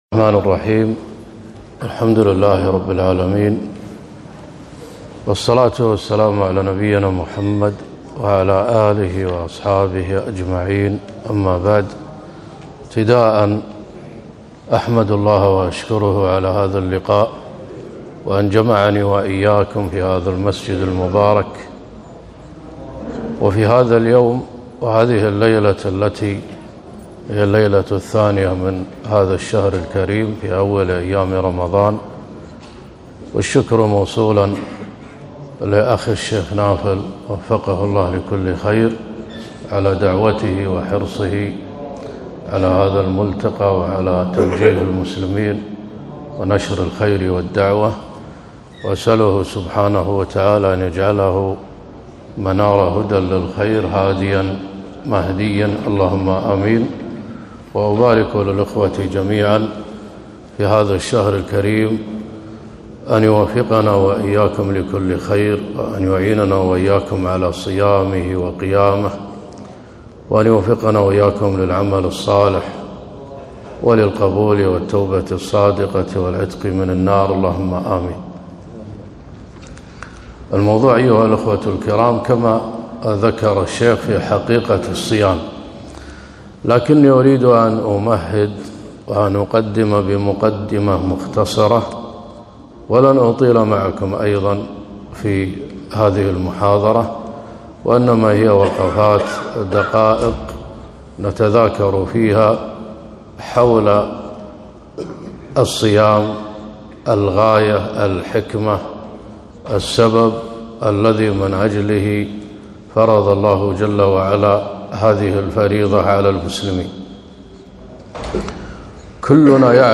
كلمة - حقيقة الصيام